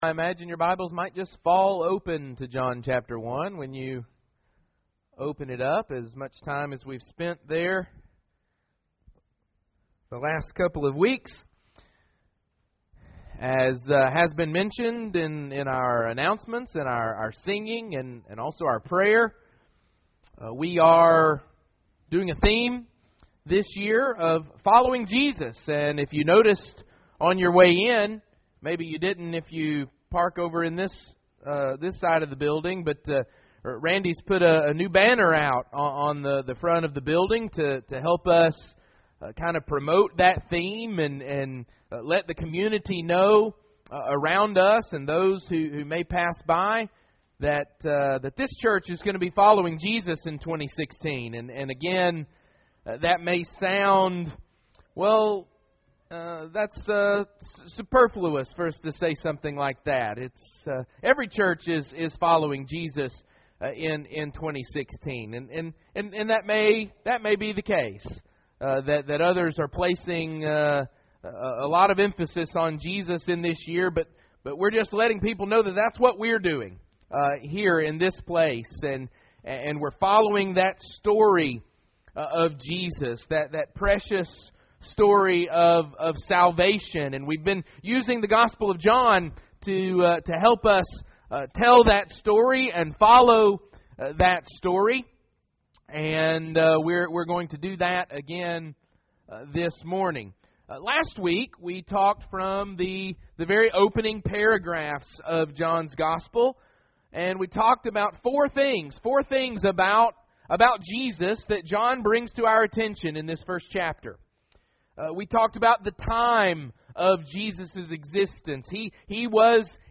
Another lesson from the “Follow Jesus in 2016” series.